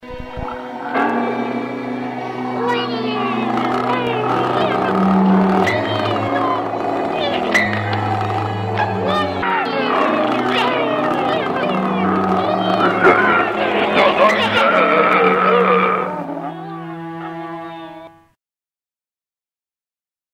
Taki żart muzyczny (MP3) - byłem głodny.